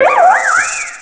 pokeemerald / sound / direct_sound_samples / cries / leavanny.aif